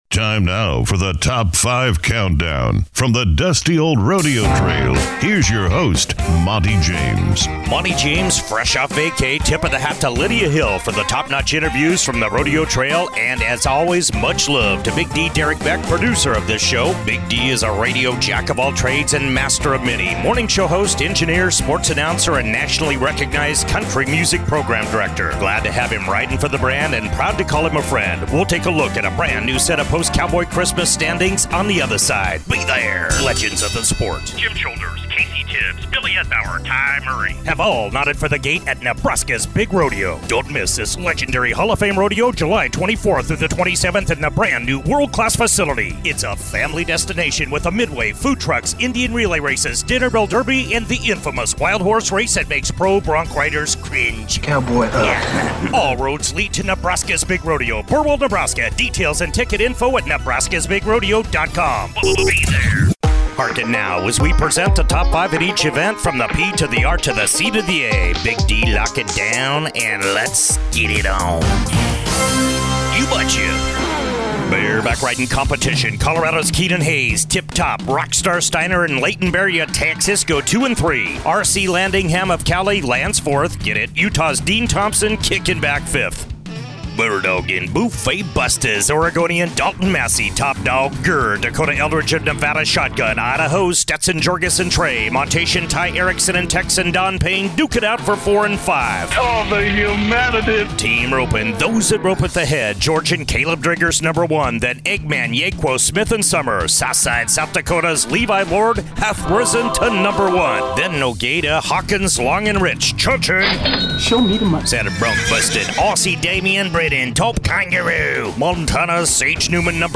Fast paced, professionally produced, weekly radio show that follows Midwestern PRCA Rodeo Athletes throughout the year & all the way to the National Finals Rodeo!